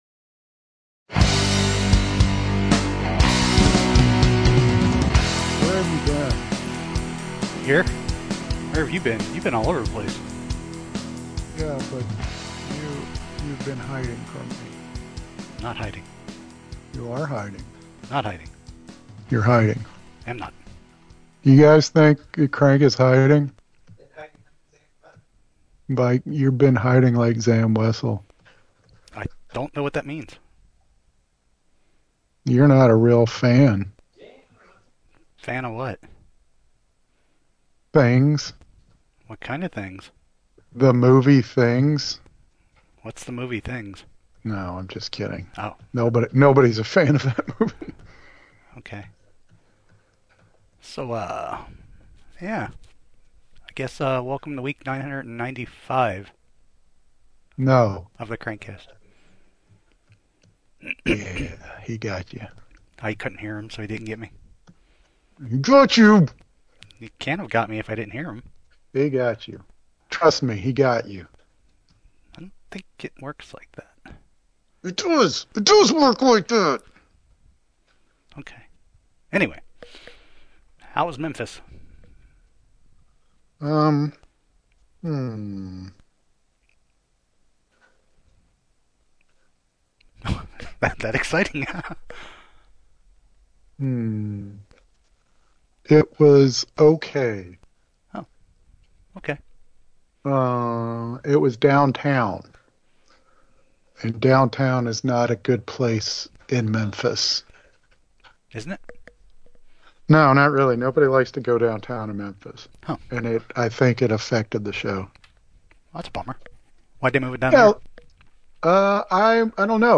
Flash forward 20 years and I thought it’d be fun to repost it since it’s been archived offline for a long time. It’s pretty rough.
For some reason we kept saying cool over and over.